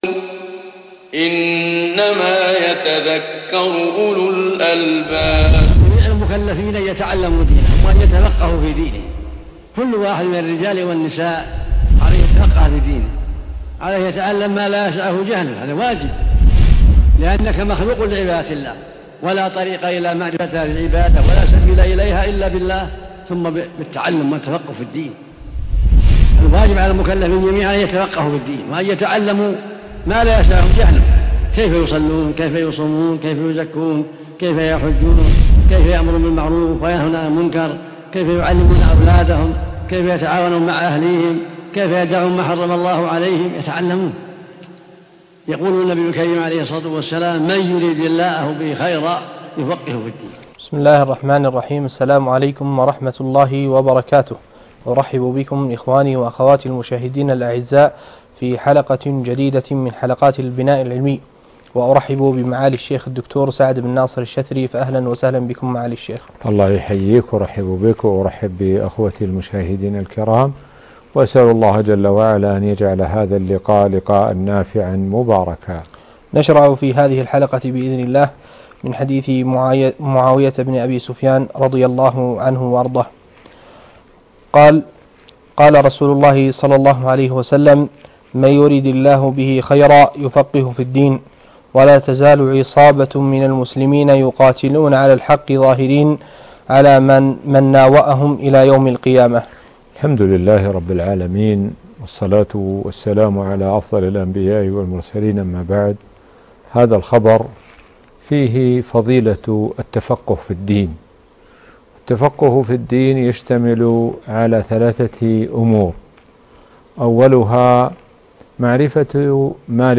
الدرس 19 (المحرر في الحديث 4 - البناء العلمي) - الشيخ سعد بن ناصر الشثري